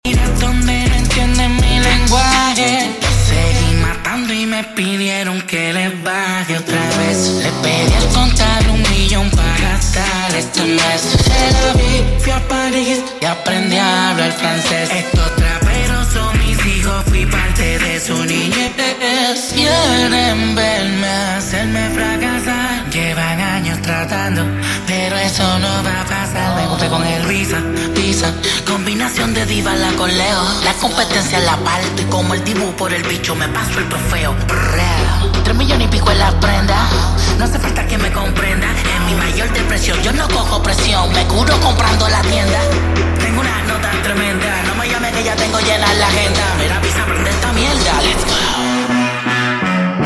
8D AUDIO